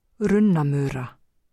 framburður
runna-mura